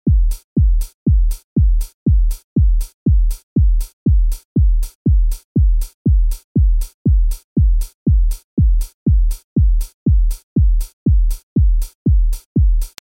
Exemples de boucles sonores
poumtchi.mp3